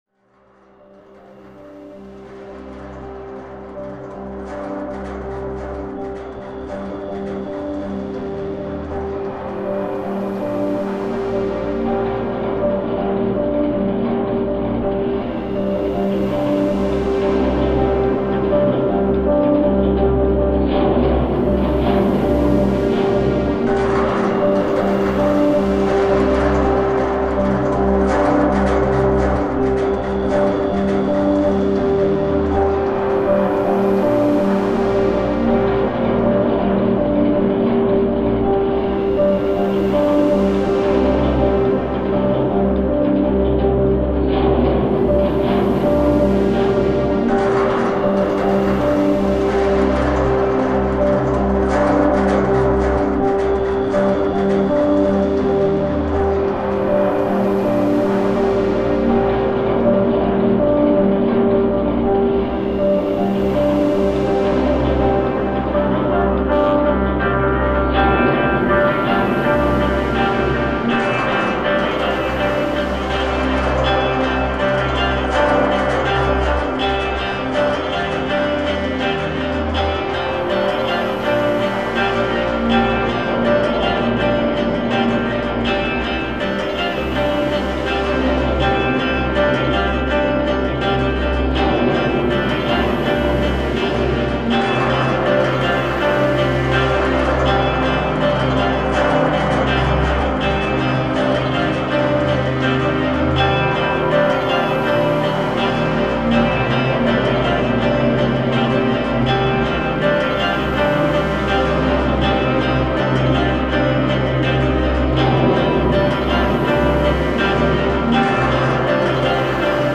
*фоновая музыка –